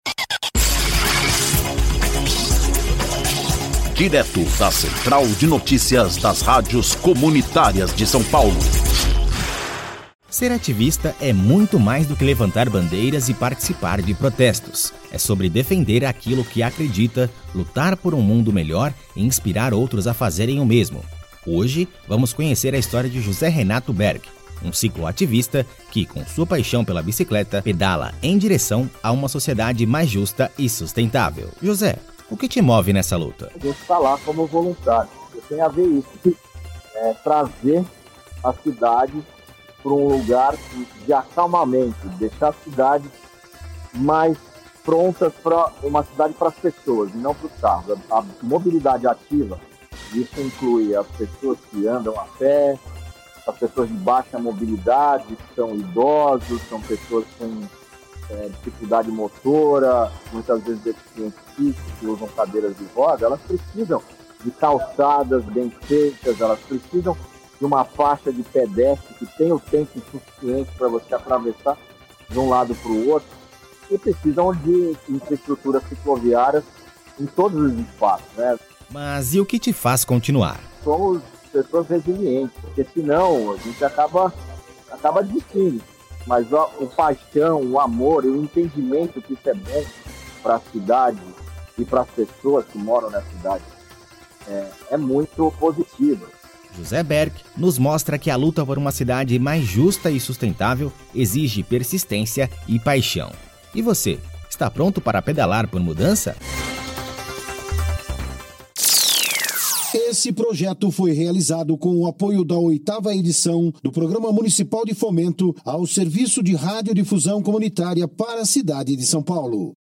INFORMATIVO: Pedalando por Mudança: a Luta de um Cicloativista por uma São Paulo Mais Justa